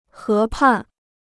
河畔 (hé pàn): riverside; river plain.